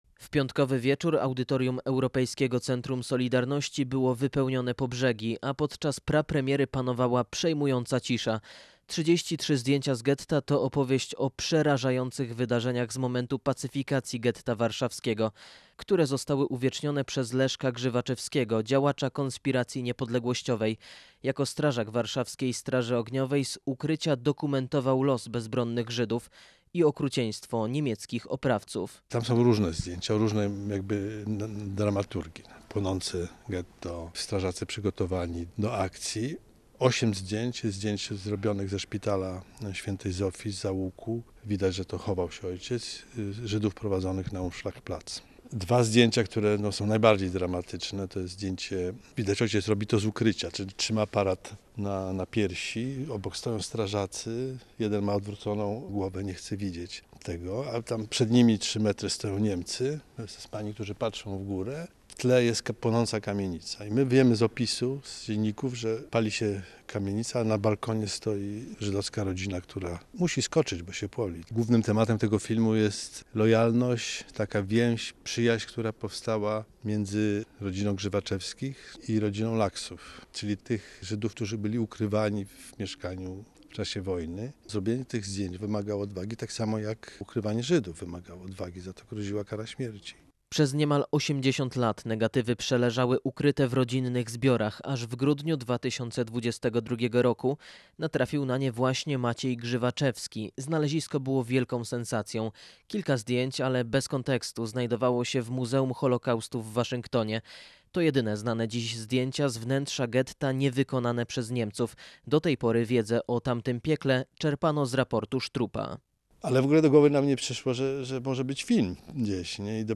Posłuchaj materiału naszego reportera